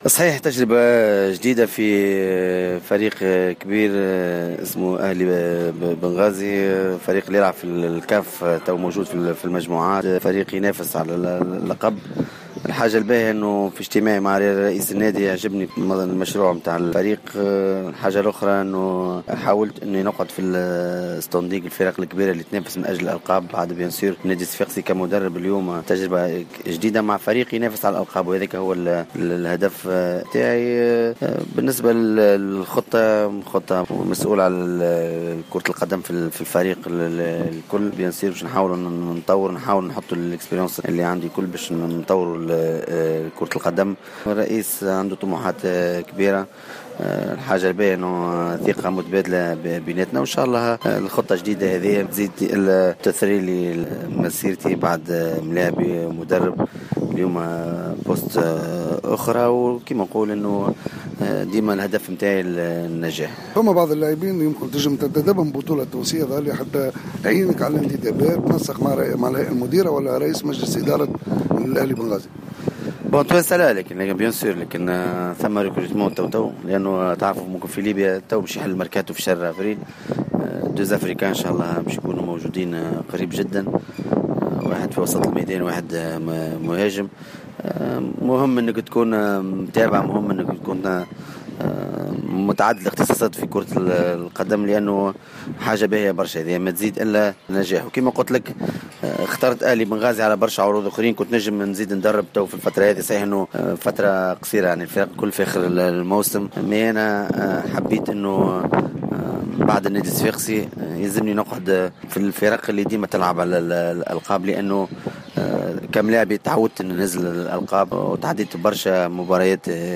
(تصريح)